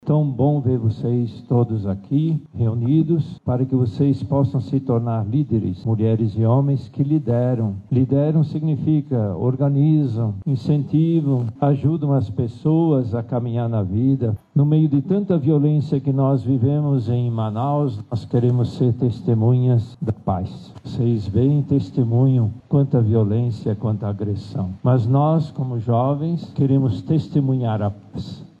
O Arcebispo Metropolitano de Manaus, Cardeal Leonardo Steiner esteve na abertura da SJL e refletiu com os jovens sobre a oportunidade de eles estarem num espaço de formação para líderes. Recordou dos jovens que também poderiam estar ali, mas estão em outros caminhos, muitas vezes marcados por desigualdades e violência.